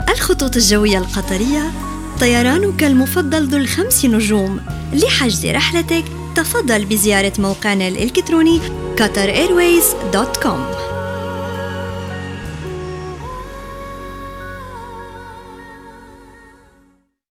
AR TS EL 01 eLearning/Training Female Arabic